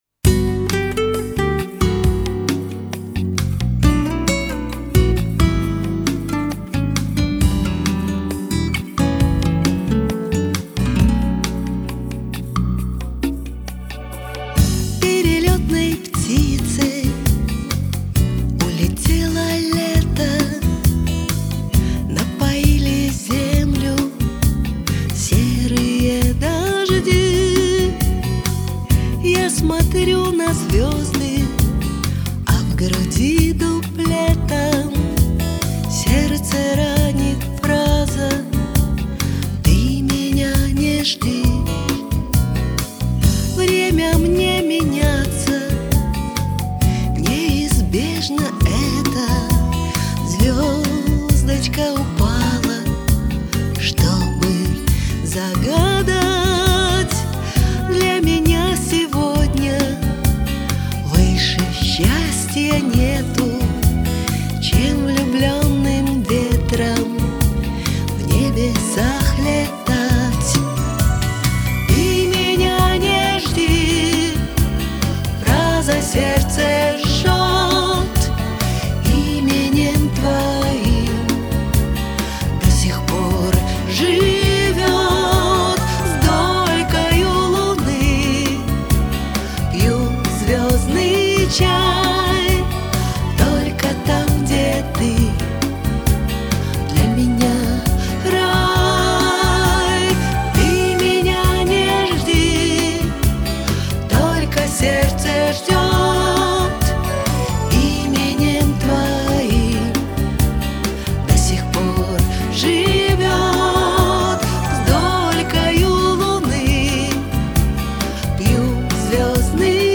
гитары
бэк-вокал
И такая лиричная!